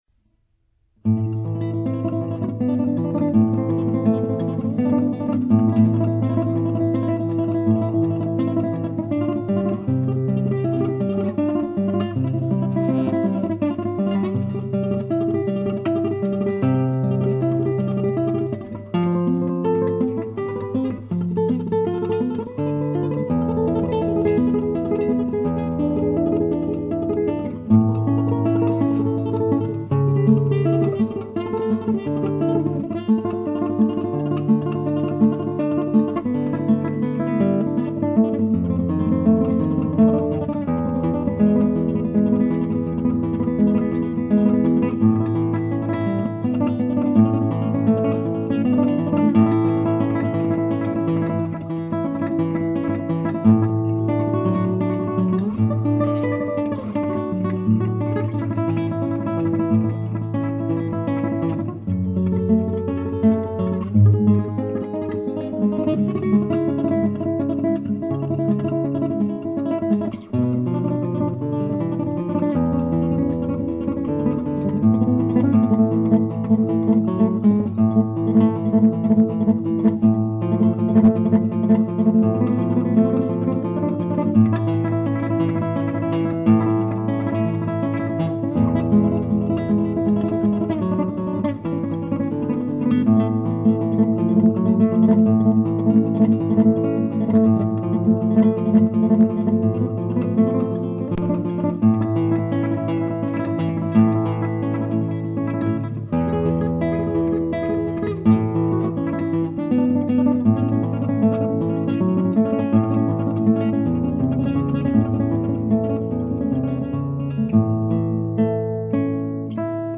Solo Guitar Demos – Click Here
SOLOS